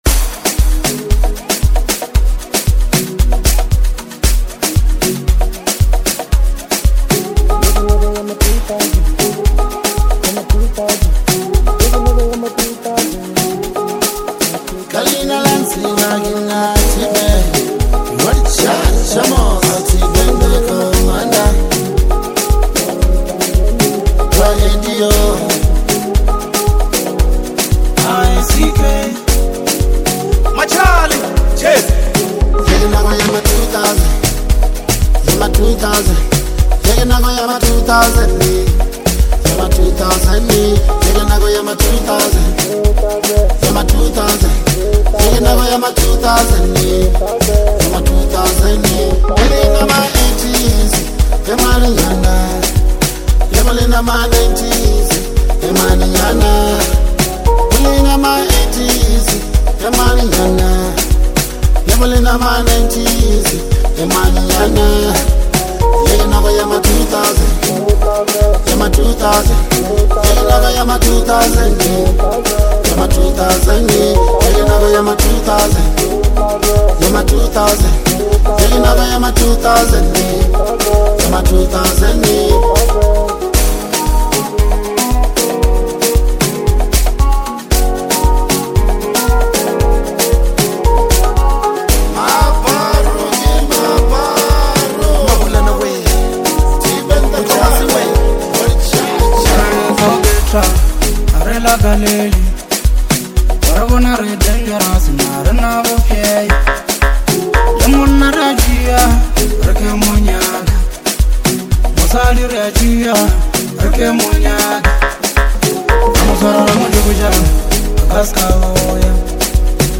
The soulful track